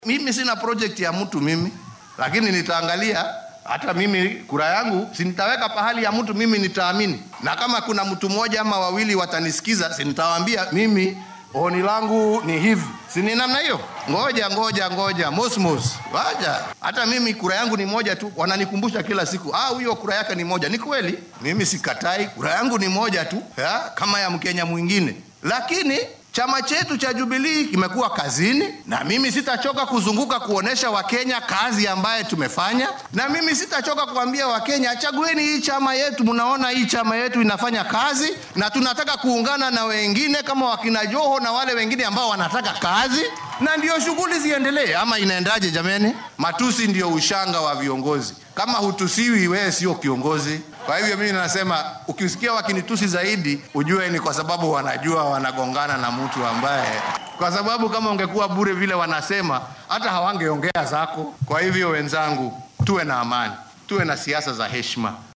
Madaxweynaha ayaa arrimahan ka hadlay xilli uu si rasmi ah u furayay shir sanadeedka shaqaalaha caafimaadka ee sanadkan, waxaa uuna sheegay in Kenya ay soo saarayso shaqaale caafimaad oo tayo leh.